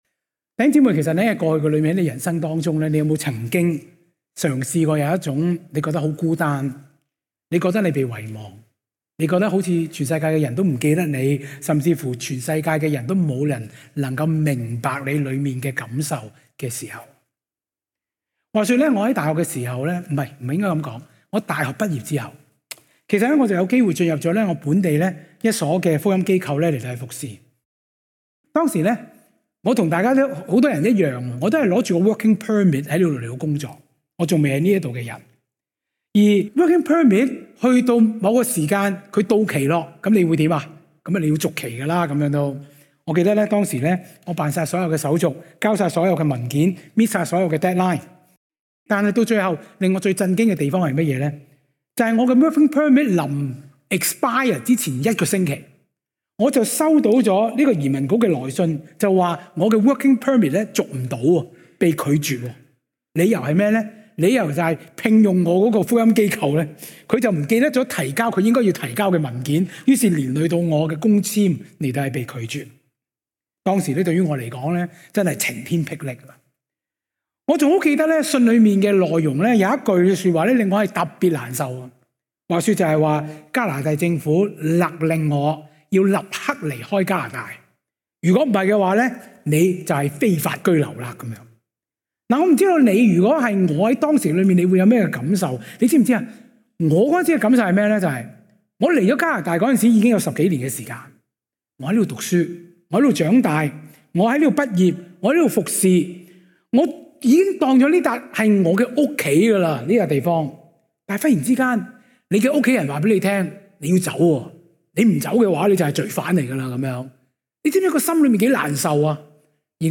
講道